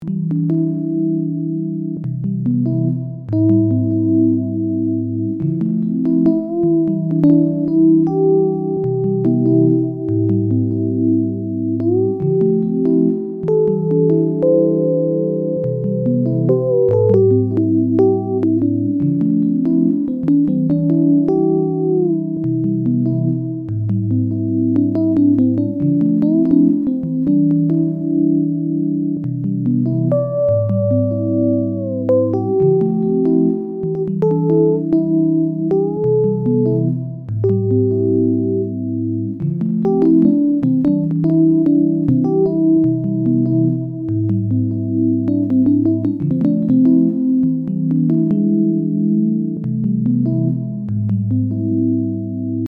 steel-solo.mp3